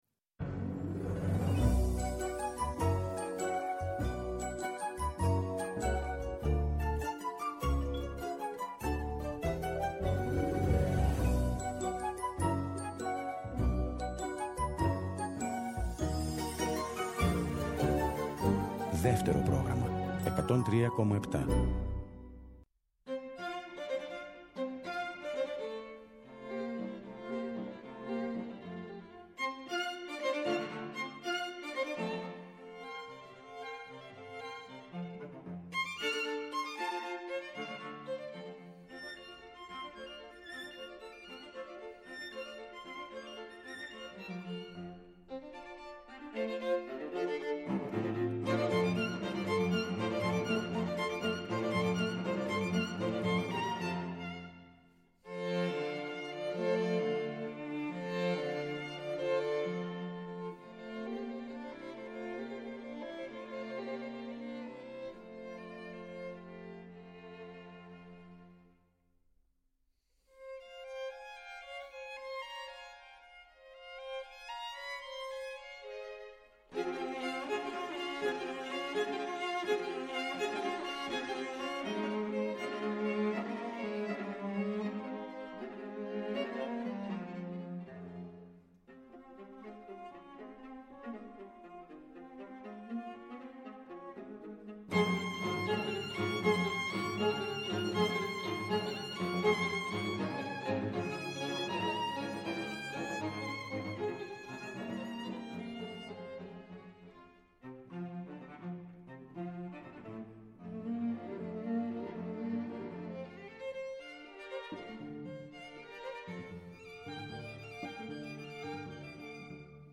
συνομιλεί στο studio του Δεύτερου